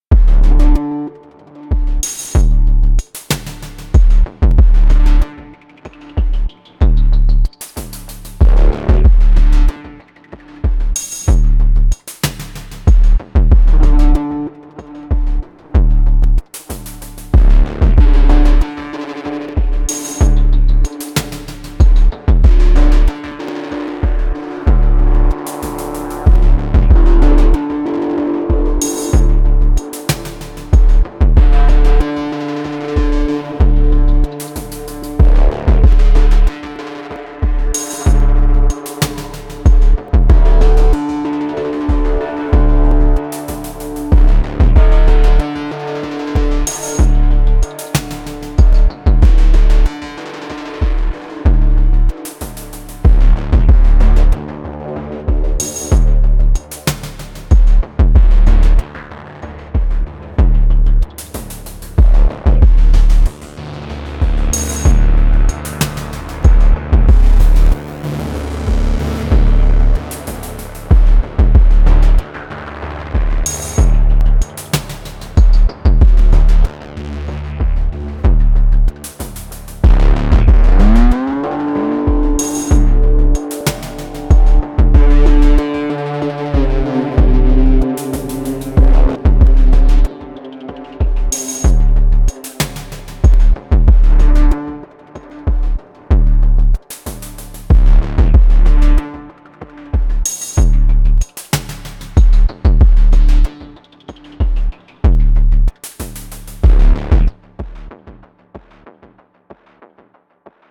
It’s a lot of money and I already have lots of cool gear so I tried doing some weird stuff on my A4 to fight GAS
Ran it through a limiter in post
But more harsh.
I was particularly happy with the kick on this one.
Wow, that IS good…I heard mentions of the A4 not being really strong low down, but I had this on headphones, and that thump almost felt like it compressed my brain.